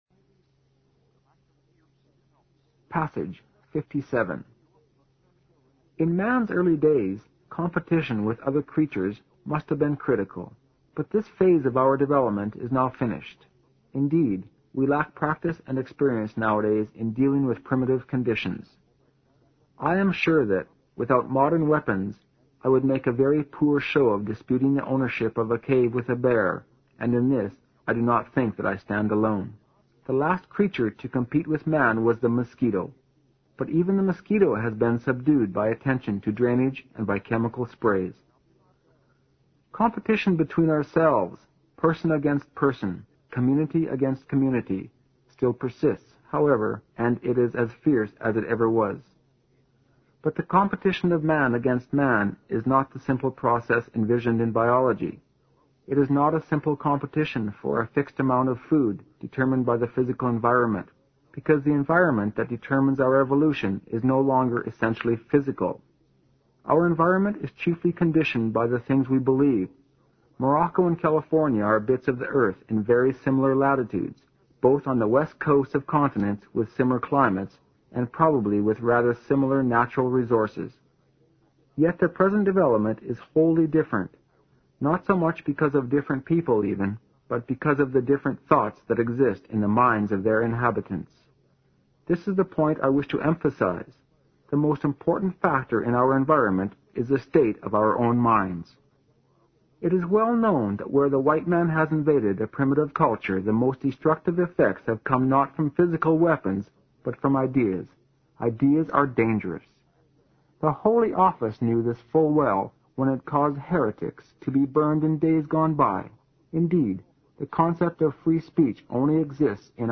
新概念英语85年上外美音版第四册 第57课 听力文件下载—在线英语听力室